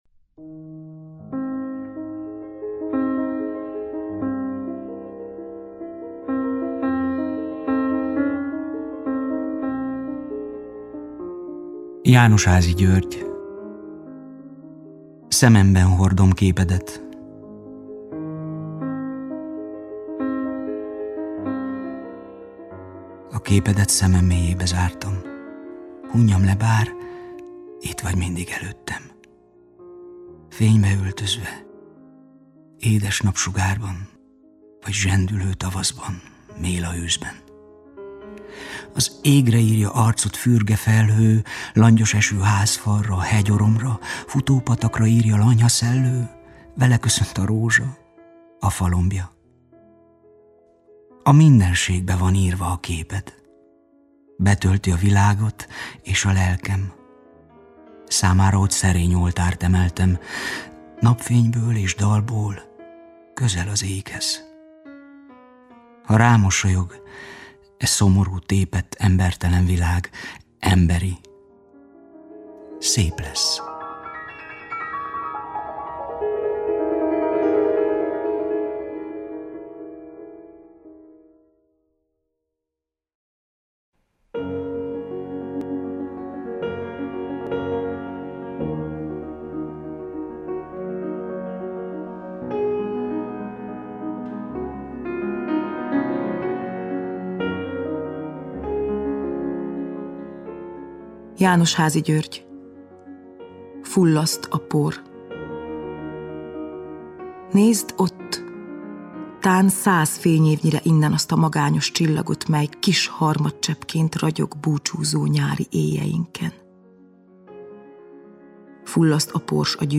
Válogatást hallanak szonettjeiből, előadják: